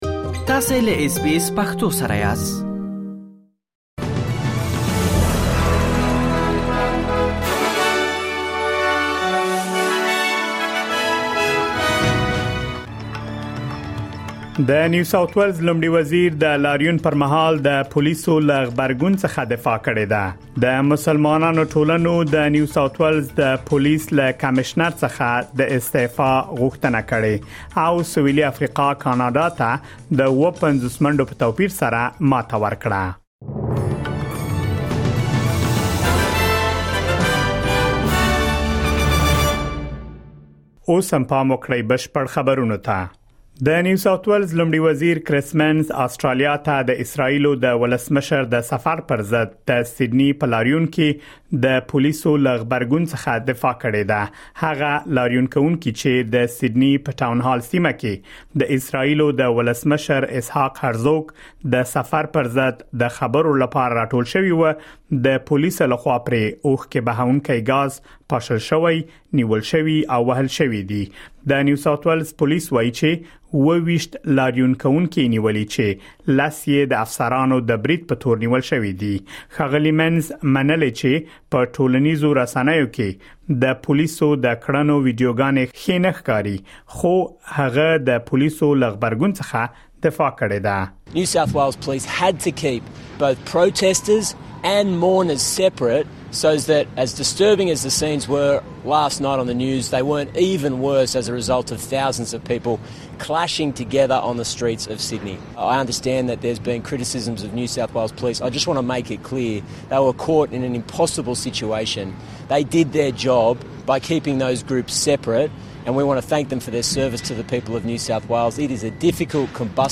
د اسټراليا او نړۍ مهم خبرونه
د اس بي اس پښتو د نن ورځې لنډ خبرونه دلته واورئ.